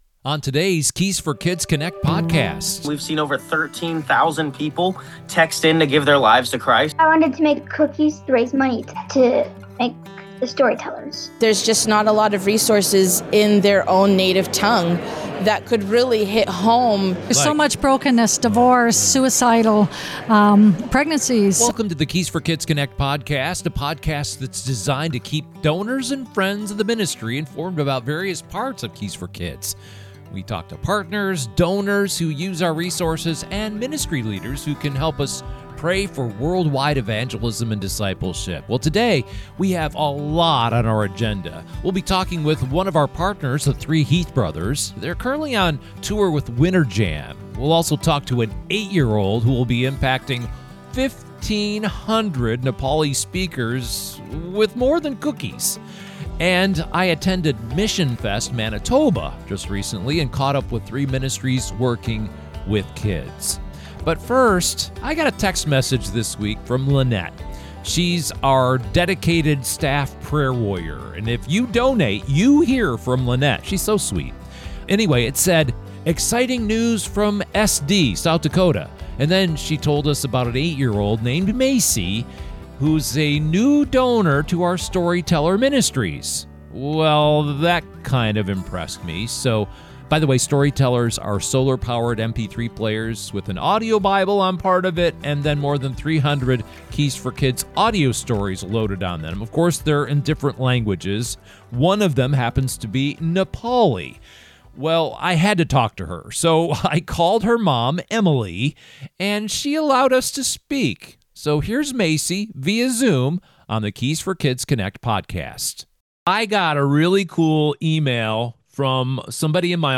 In this powerful March episode of Keys for Kids Connect, we take you from the high-energy stages of Winter Jam to the remote mountains of Nepal — where God is moving in incredible ways through kids, music, and faithful believers around the world. We sit down with the 3 Heath Brothers as they tour with Winter Jam and share their heart for reaching the next generation for Christ.